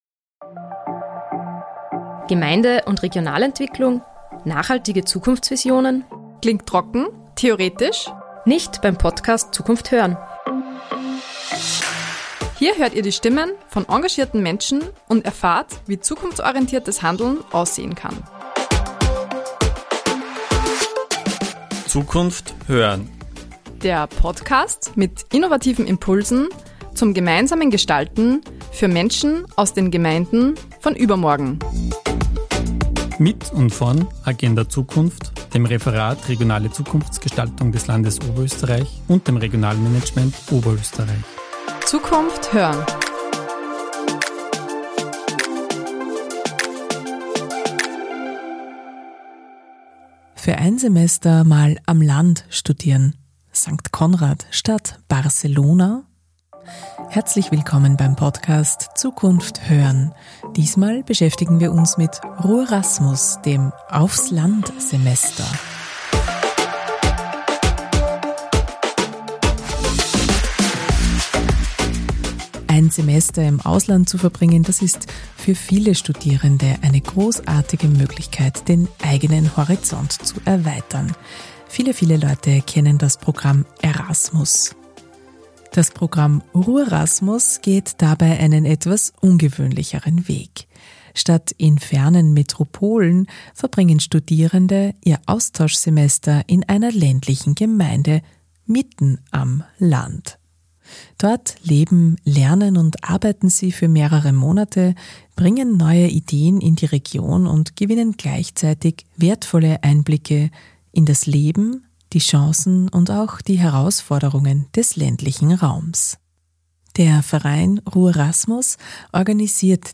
Das Zukunft.hören-Podcast-Team war vor Ort und hat spannende Gäste ins Studio geholt, die RURASMUS selbst erlebt, begleitet oder mitgestaltet haben.